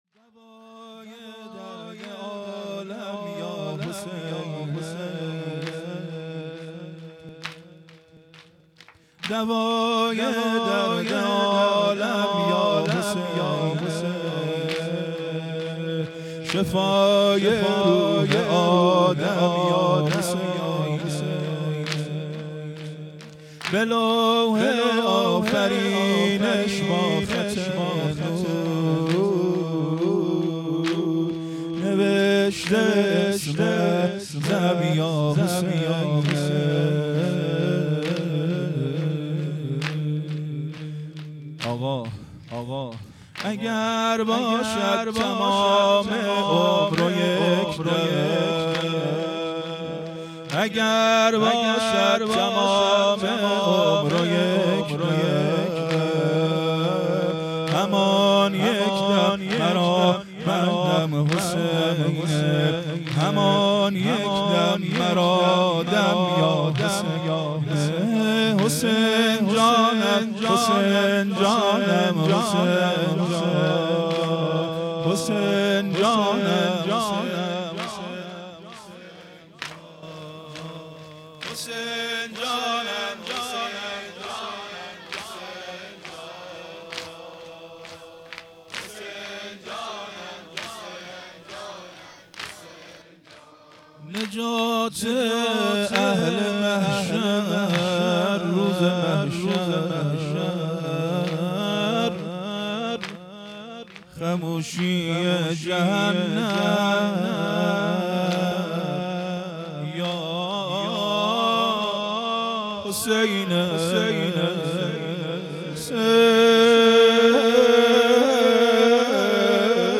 محرم ۱۴۴۵_ شب دوم